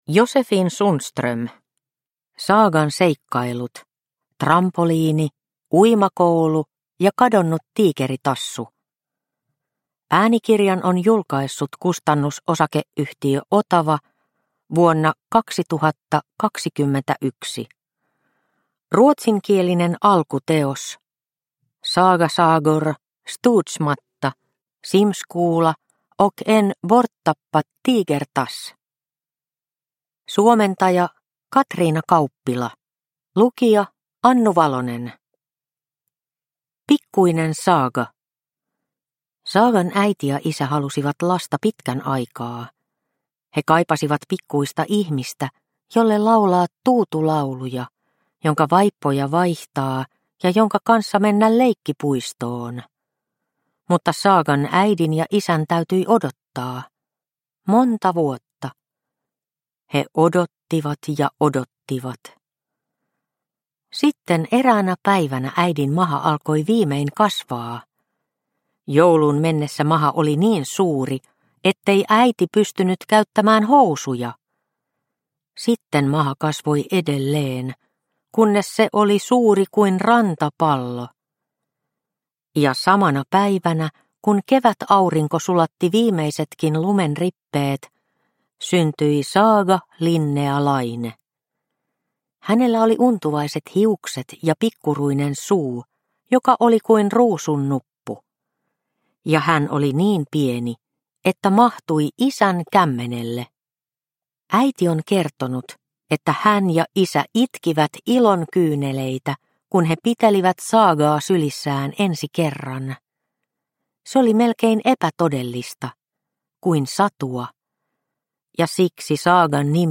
Saagan seikkailut -Trampoliini, uimakoulu ja kadonnut Tiikeritassu – Ljudbok – Laddas ner